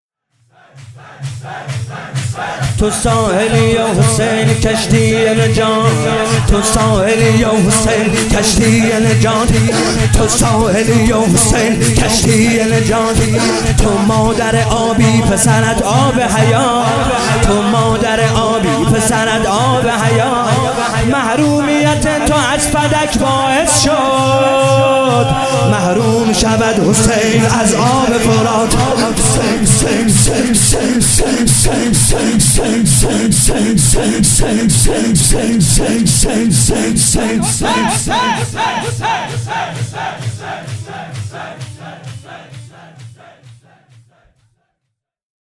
كربلايی حسين طاهری
هفتگی 24 بهمن 96 - شور - تو ساحلیو حسین کشتی نجات